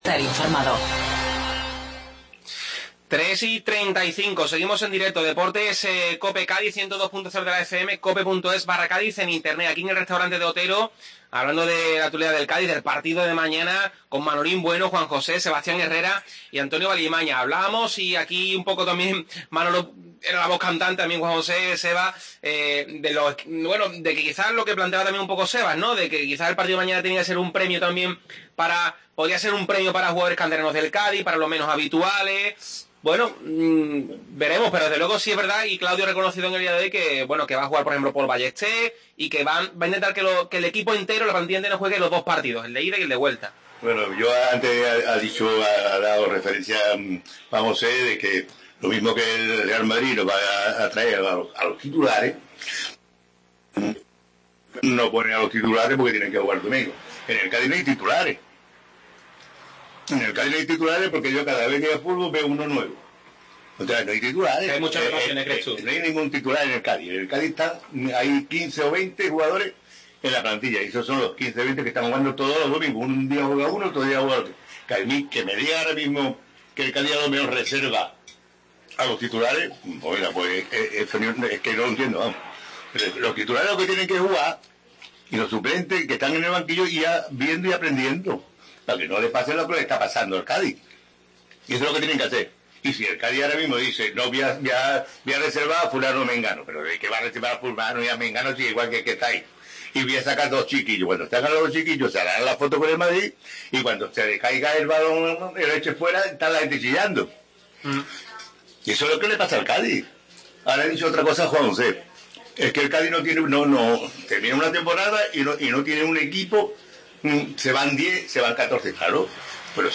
AUDIO: Segunda parte de la tertulia desde el Restaurante De Otero